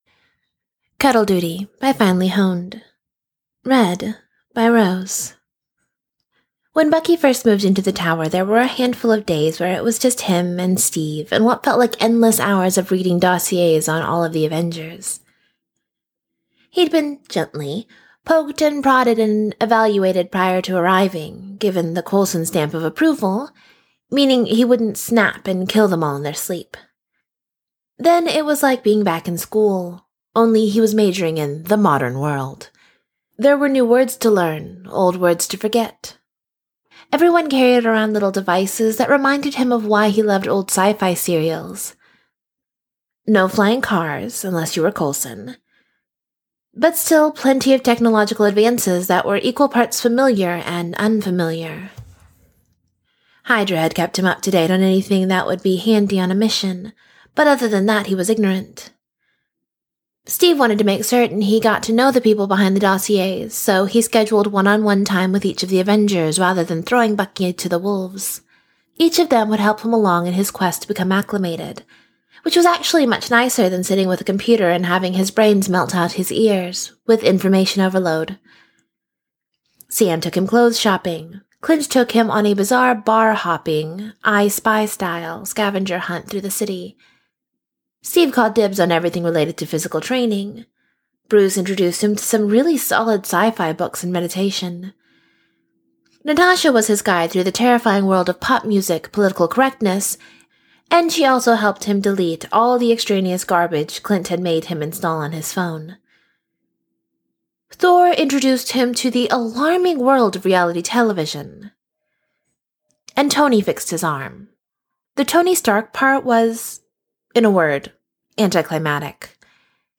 [Podfic] Cuddle Duty